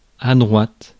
Ääntäminen
IPA: [dʁwat]